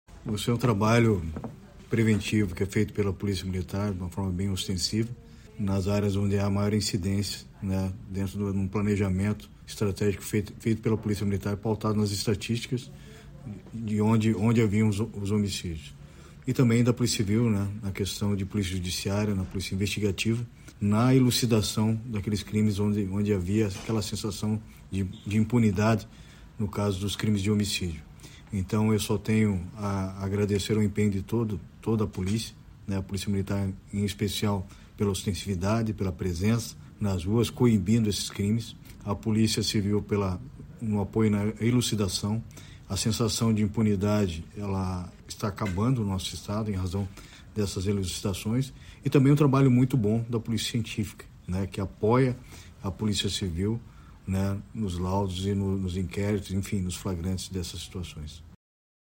Sonora do secretário de Segurança Pública, Hudson Leôncio Teixeira, sobre os índices de homicídios no primeiro quadrimestre de 2023 no Paraná